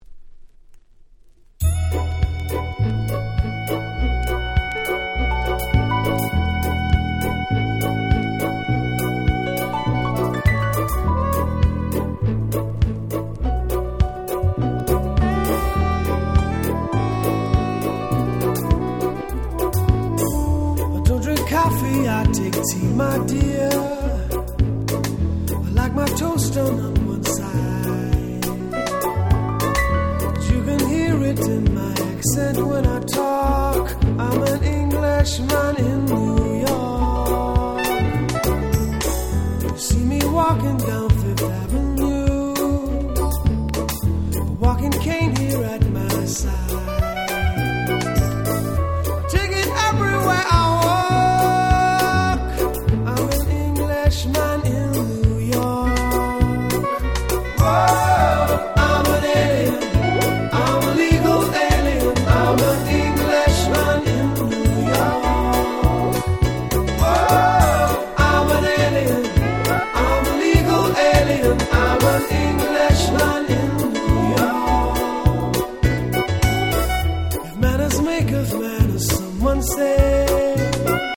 88' Super Hit Pops !!
AOR